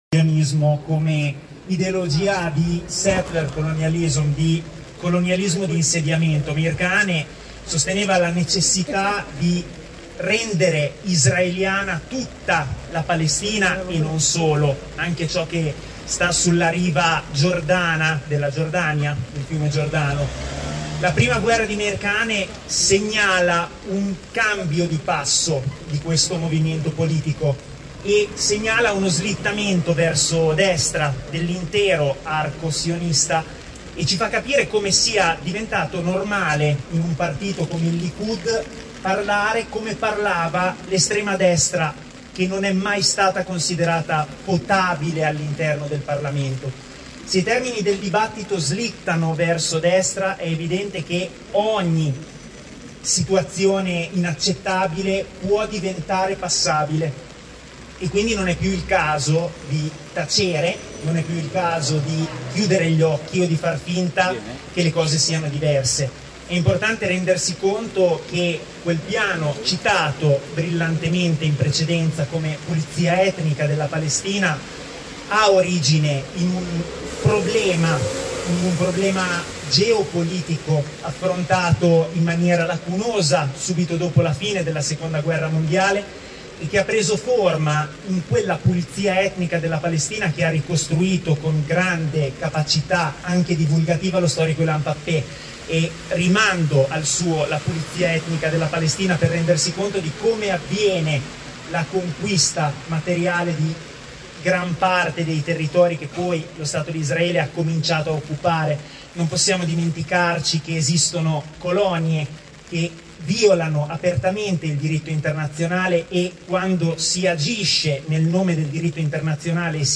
In piazza per No alla deportazione del popolo palestinese.